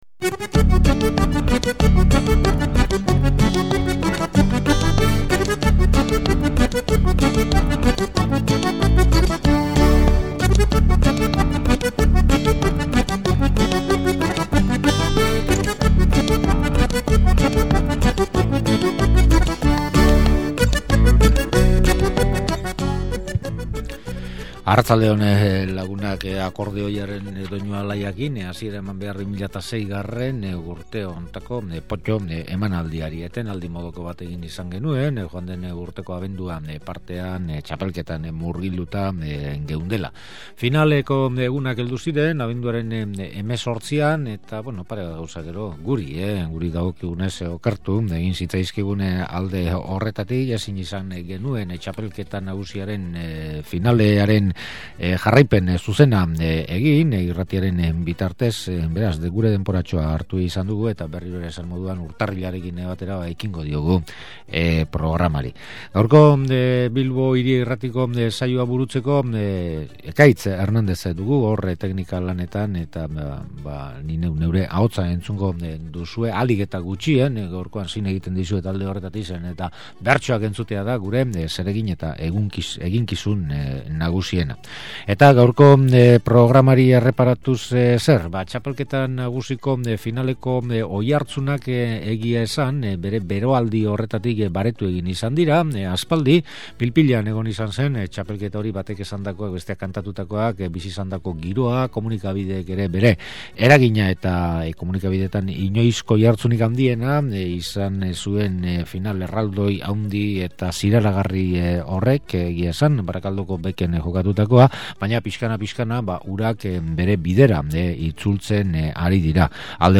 Beste muturrean, bertsolari gazteei dagokien muturrean, iazko otsailean Donazaharreko Mendi jatetxean antolatutako bertso afarian Sustrai Colinak eta Amets Arzallusek botatako bertso sorta batzuk.